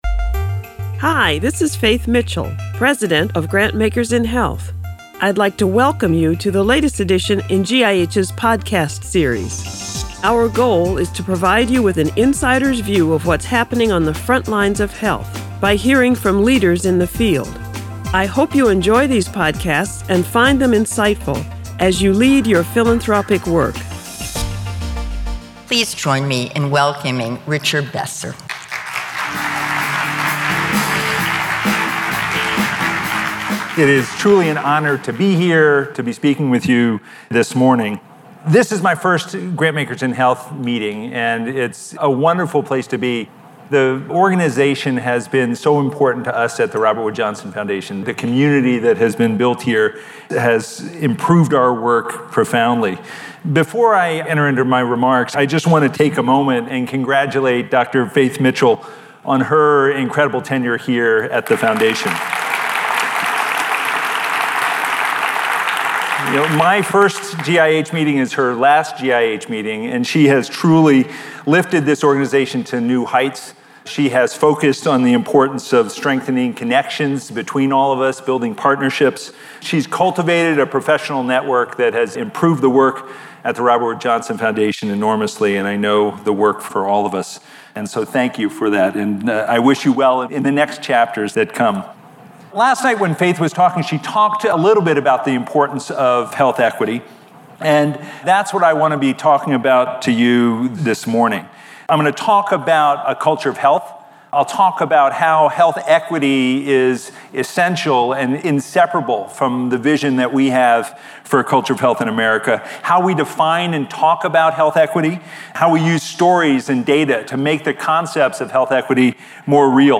2019 Annual Conference Plenary Remarks: Richard Besser - Grantmakers In Health